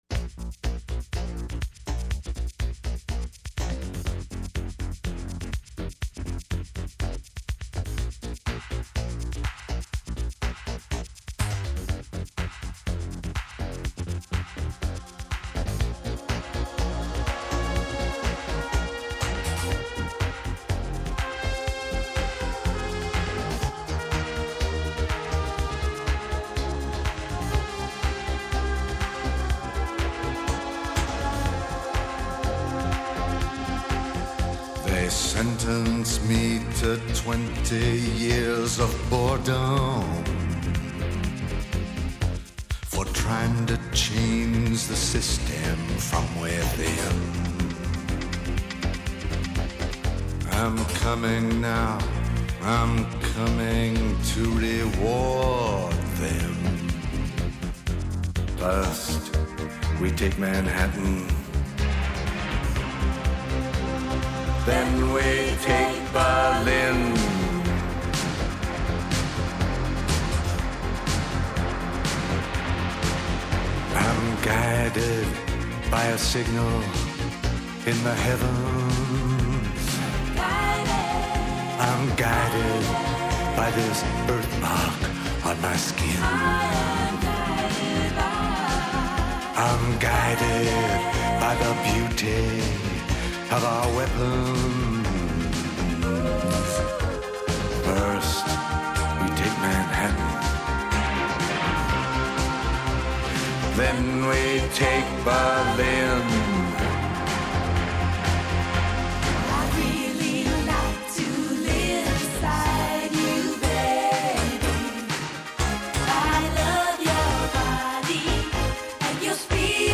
Pop, Folk, Vocal